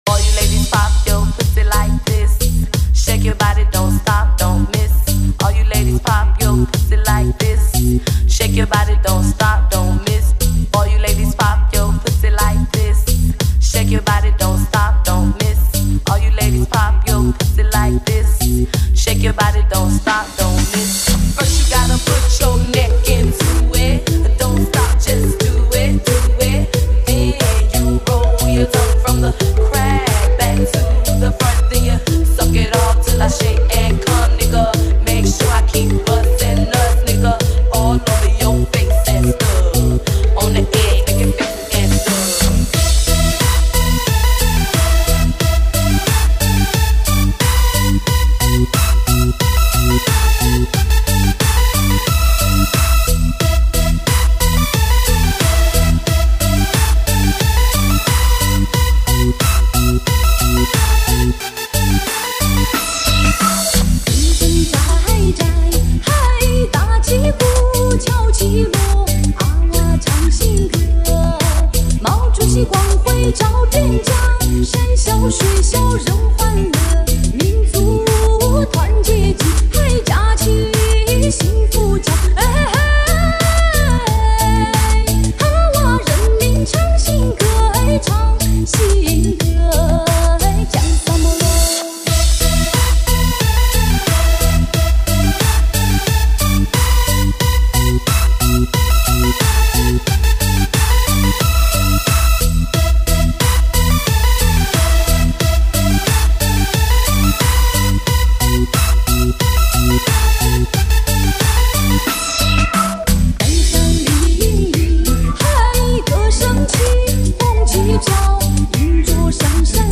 唱片类型：DJ
解禁香巴拉的千年传奇，年度西部DJ混音巨作，